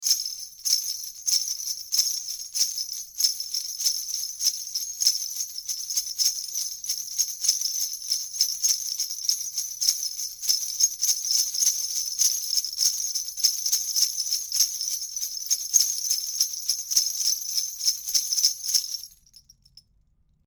Here are 39 quick, 1-take MP3s of these mics into a Presonus ADL 600 preamp with a Rosetta 200 A/D converter. This is straight signal with no additional EQ or effects:
PERCUSSION:
Dfly2PercBells.mp3